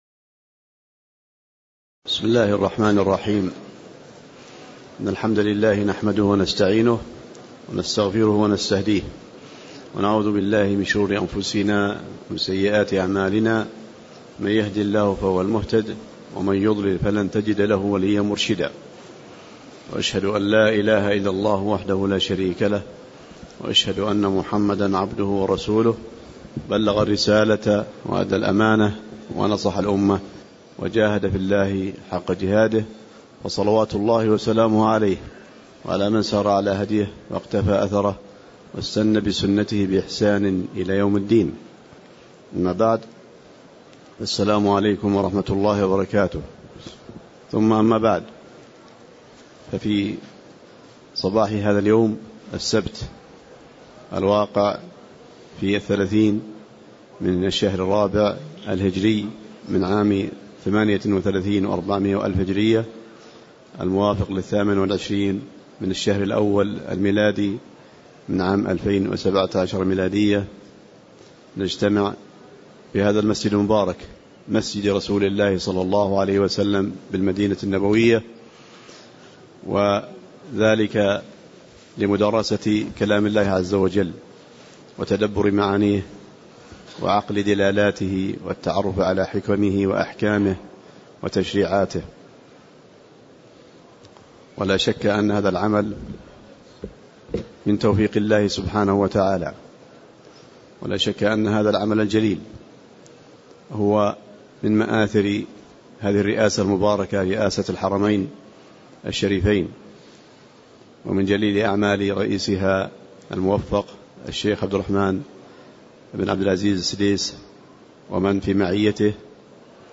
تاريخ النشر ٣٠ ربيع الثاني ١٤٣٨ هـ المكان: المسجد النبوي الشيخ